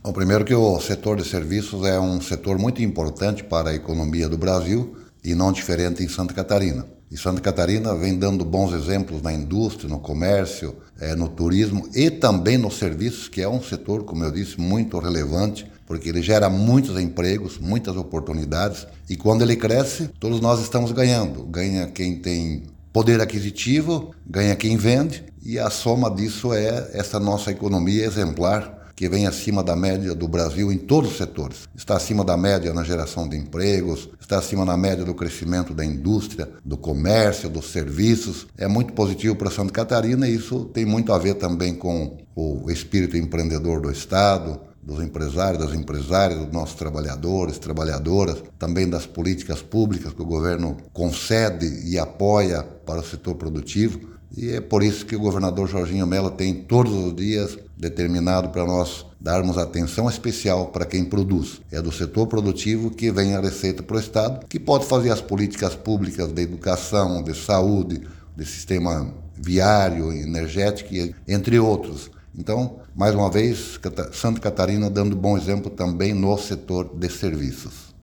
O secretário de Estado de Indústria, Comércio e Serviço, Silvio Dreveck, avalia os resultados: